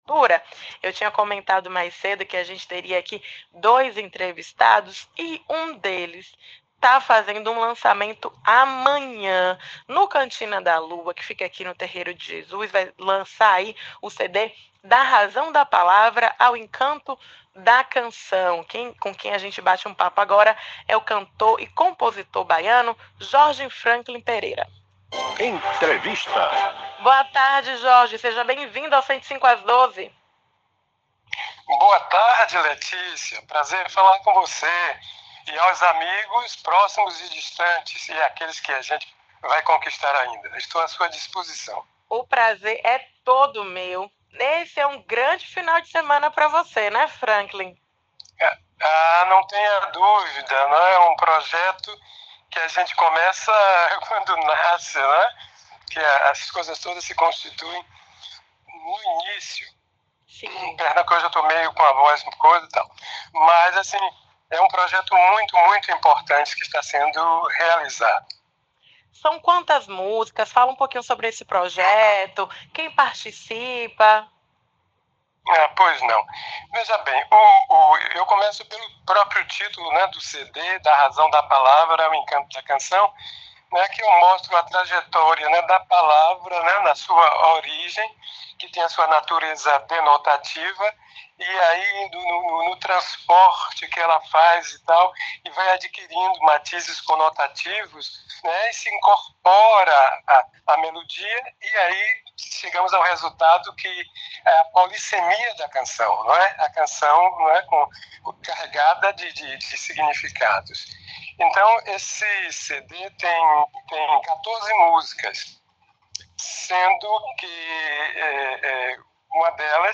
Entrevista na Rádio